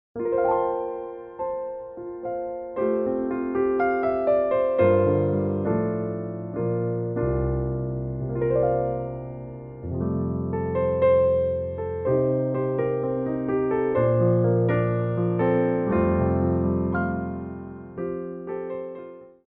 Piano Arrangements of Pop & Rock for Ballet Class
4/4 (8x8)